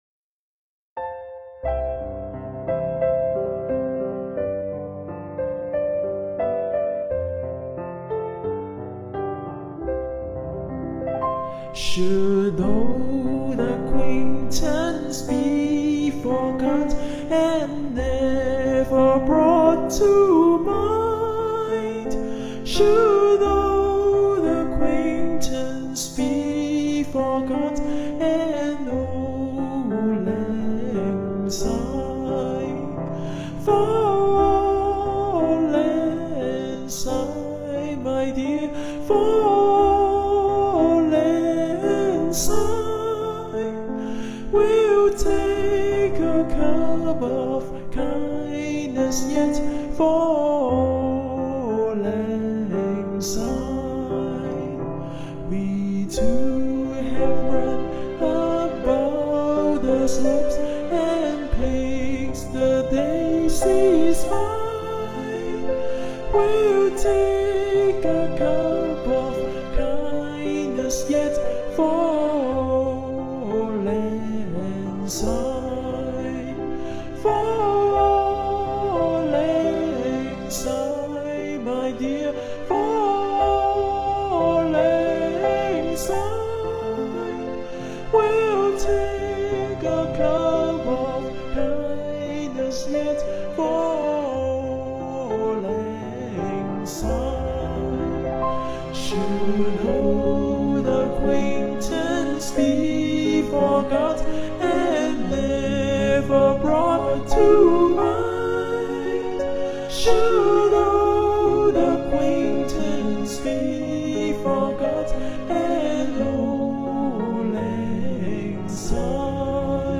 Mekong NYC hosts a music class to teach students how to play the đàn tranh (Vietnamese zither).
auld-lang-syne-with-lyrics_c_major__bpm_89.mp3